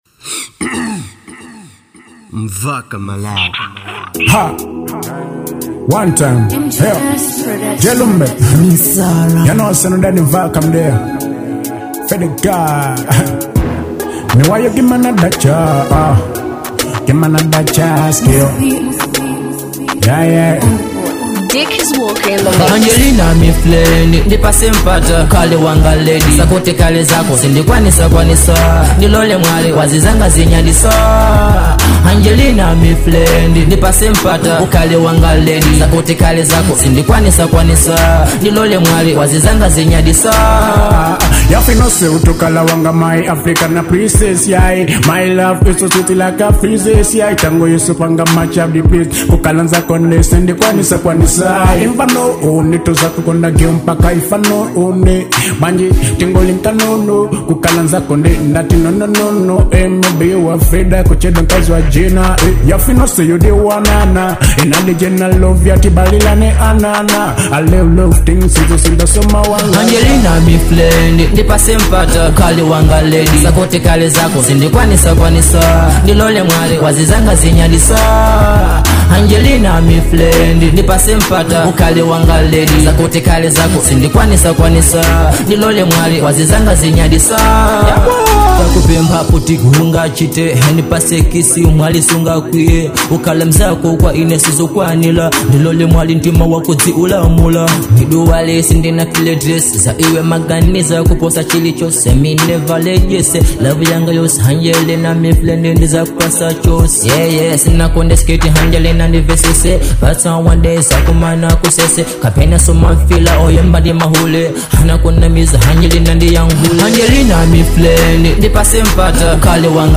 type: GODPEL